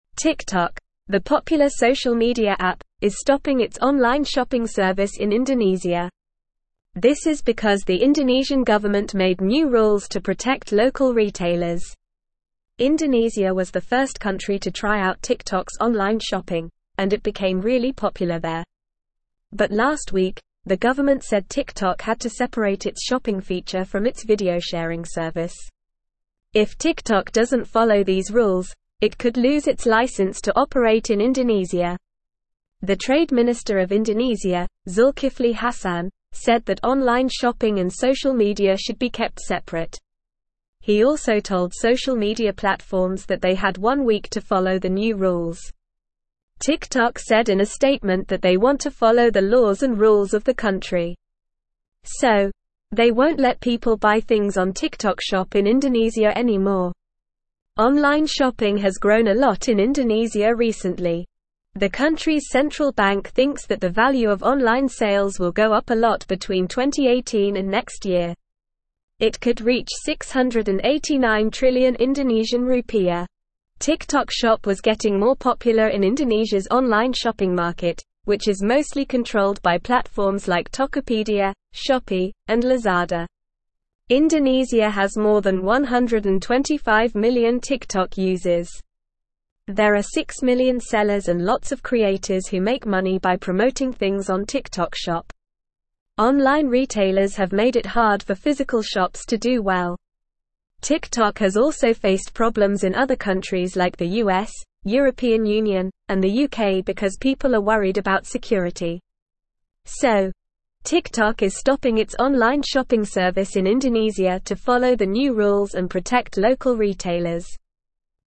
Normal
English-Newsroom-Upper-Intermediate-NORMAL-Reading-TikTok-Suspends-Online-Shopping-in-Indonesia-to-Comply.mp3